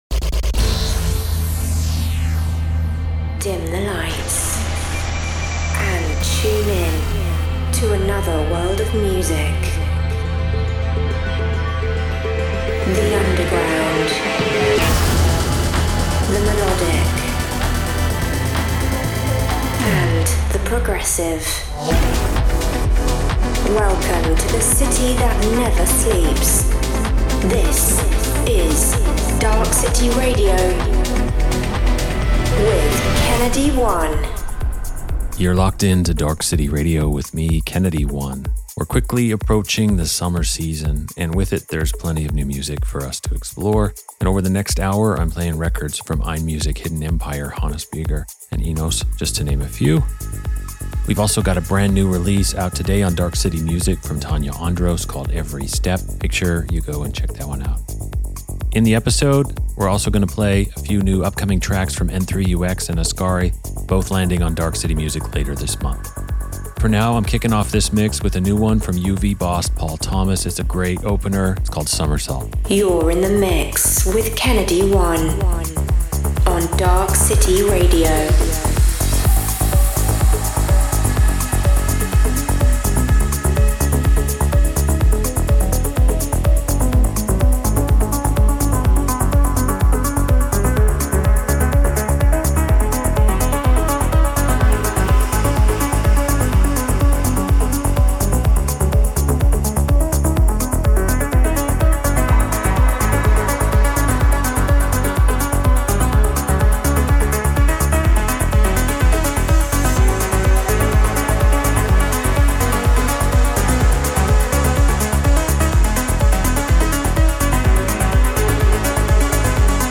underground melodic techno